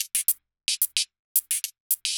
Index of /musicradar/ultimate-hihat-samples/110bpm
UHH_ElectroHatA_110-01.wav